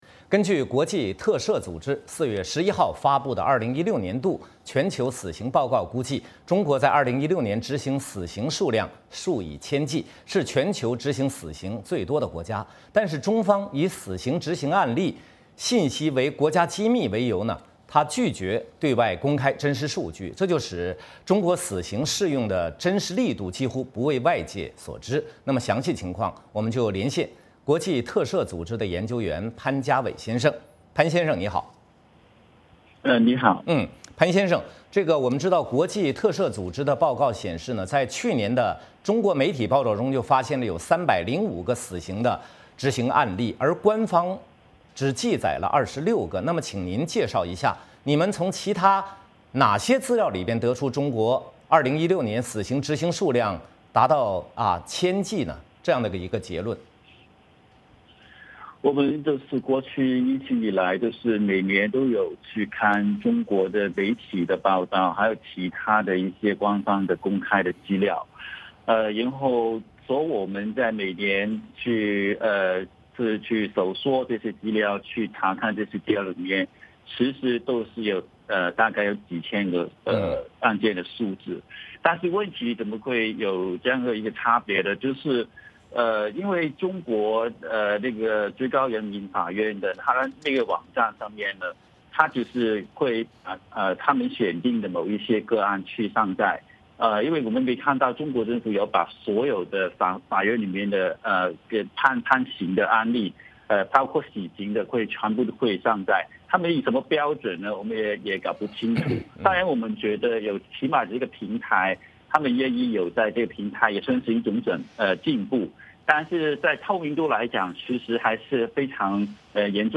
VOA连线：国际特赦：中国是2016执行死刑最多的国家